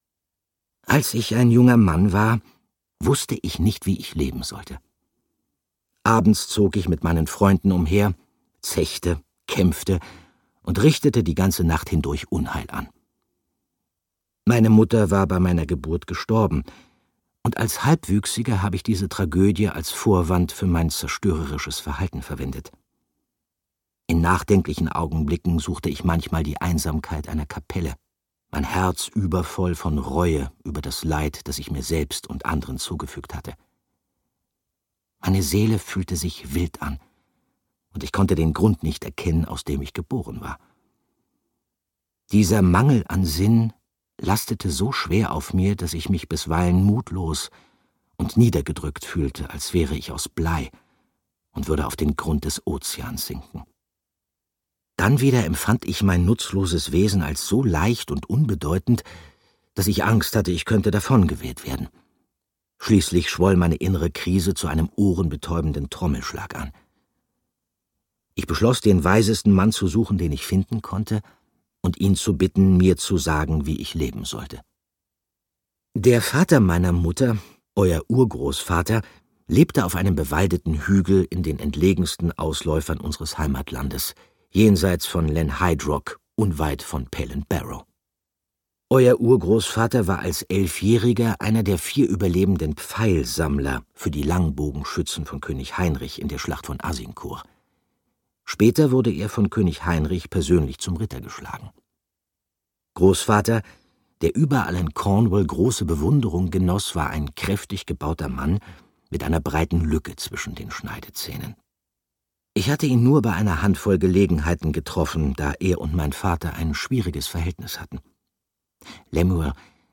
Regeln für einen Ritter - Ethan Hawke - Hörbuch